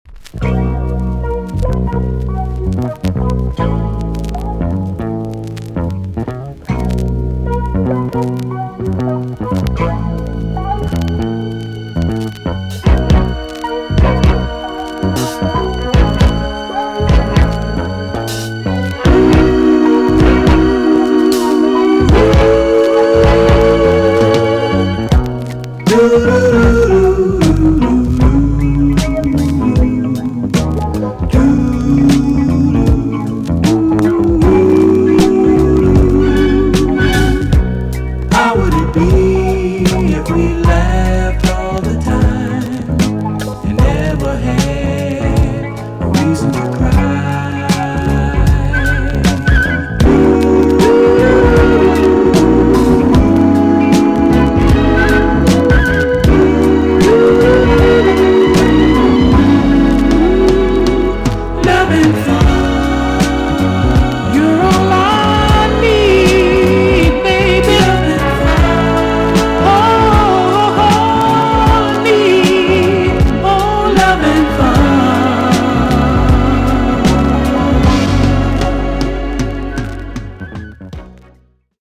EX-~VG+ 少し軽いチリノイズがありますが良好です。